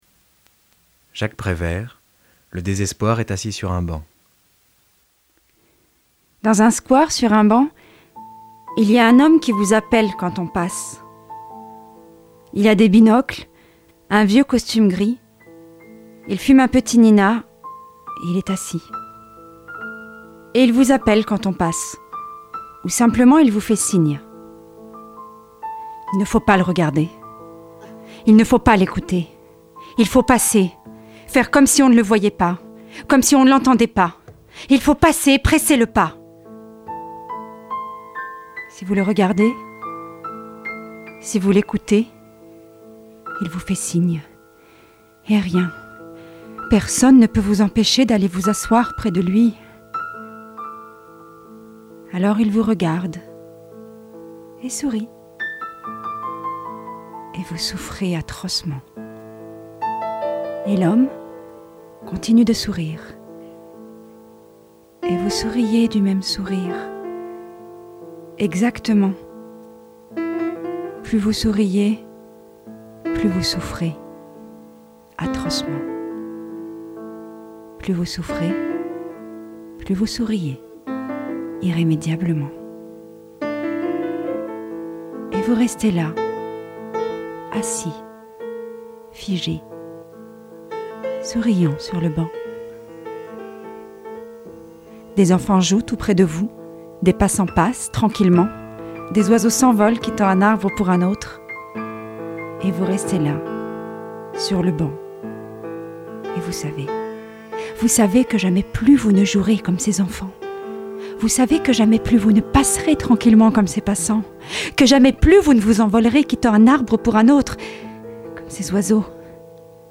Voix off
extrait du Cd LECTURES